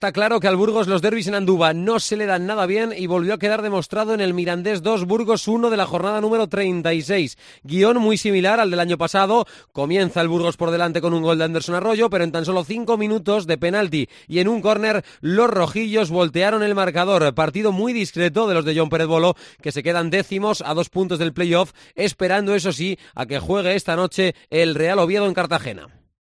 CRONICA MIRANDES 2-1 BURGOS CF